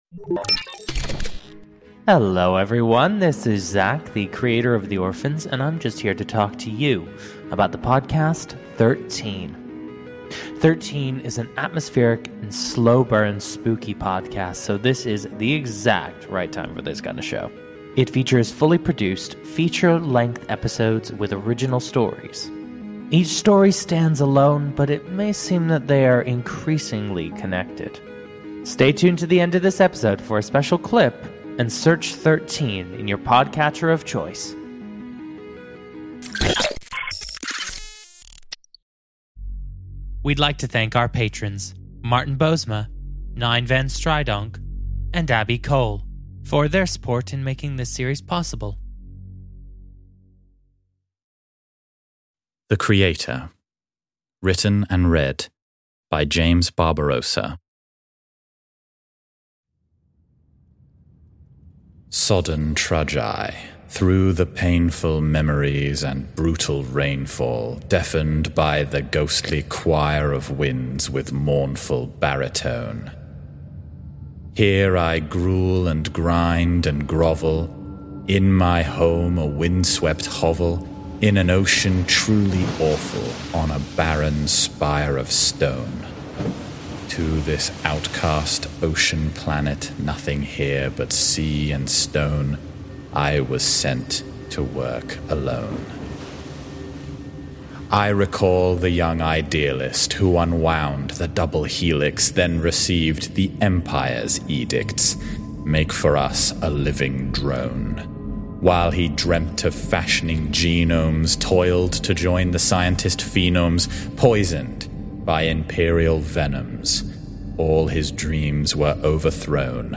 Content Warnings: -No Jump Scares